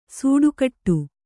♪ sūḍu kaṭṭu